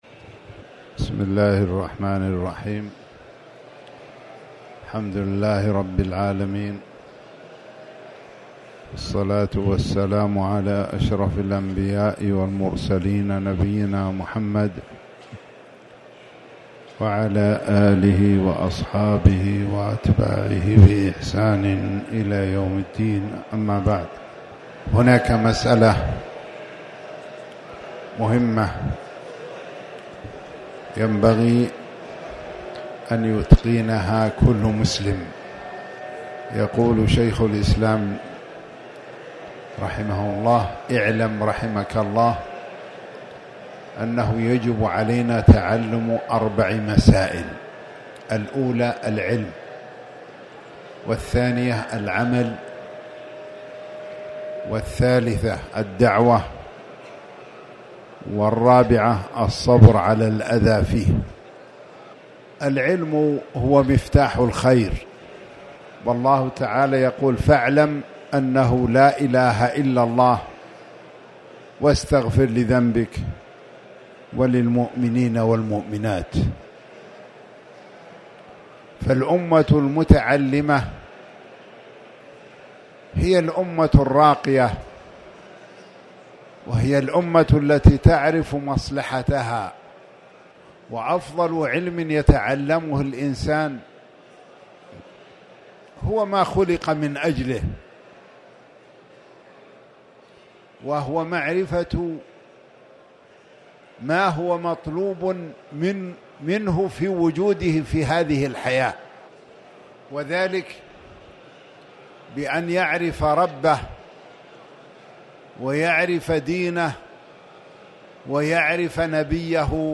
تاريخ النشر ٤ ذو الحجة ١٤٣٩ هـ المكان: المسجد الحرام الشيخ